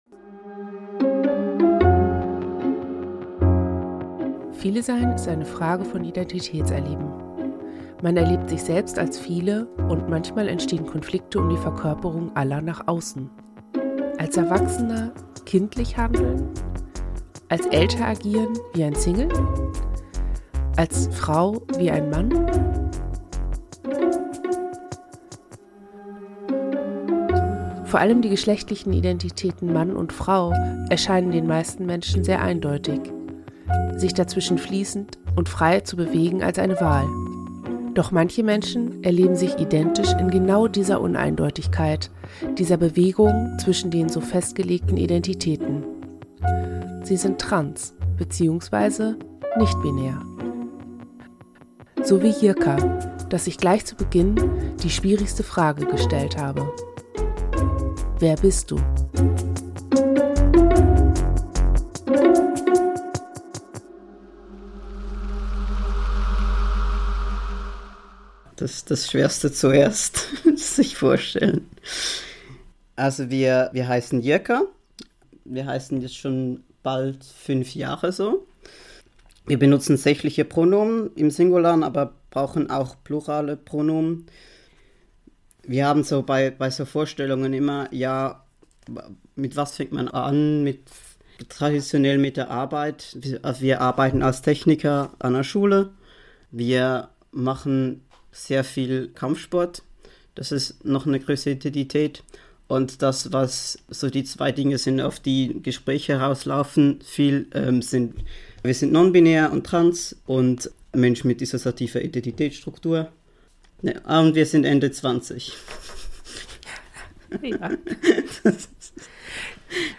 Wir reden über die Dinge, die ihre Leben bestimmen und bereichern, sie wachsen lassen und mit Kraft erfüllen. Diese Interviewreihe ist ein Projekt der Initiative Phoenix, dem Bundesnetzwerk für angemessene Psychotherapie und wird mit Spenden finanziert.